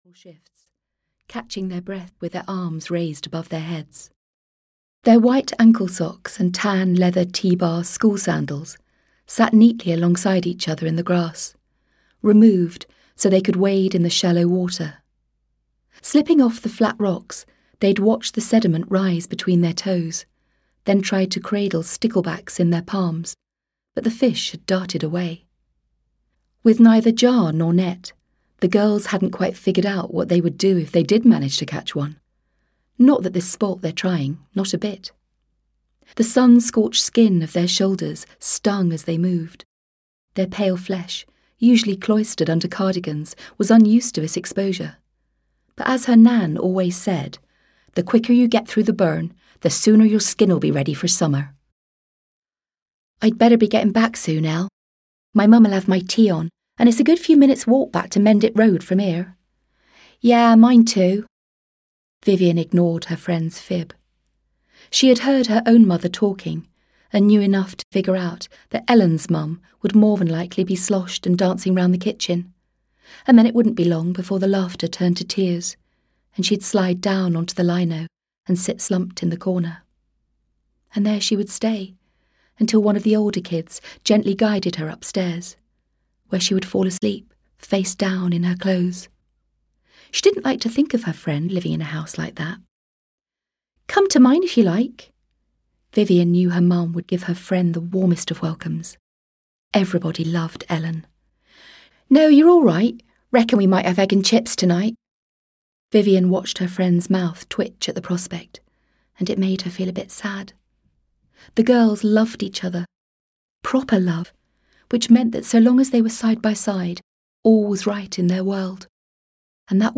I Won't Be Home For Christmas (EN) audiokniha
Ukázka z knihy
• InterpretAmanda Prowse